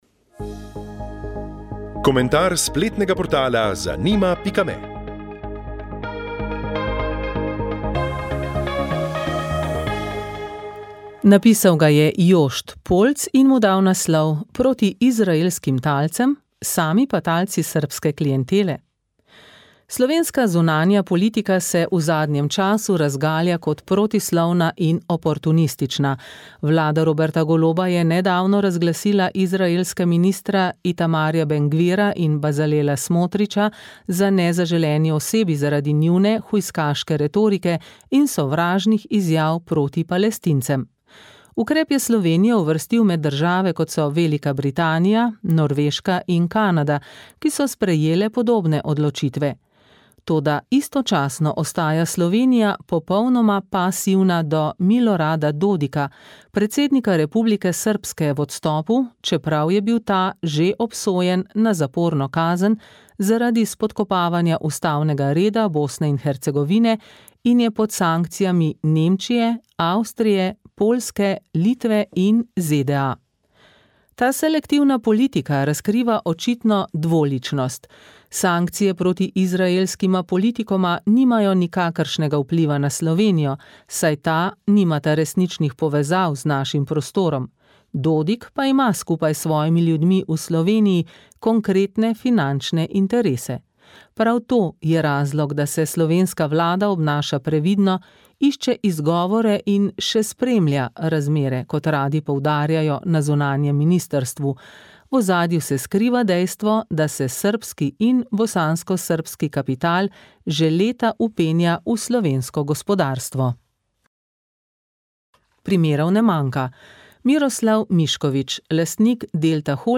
Marmelada iz kakija, rulada, piškoti za diabetike, plevel ločiti od ajdove kaše, musaka z jajčevci, testo za žlikrofe, zamrzovanje grozdja, marmelada iz grozdja ... so bile teme v tokratni kontaktni oddaji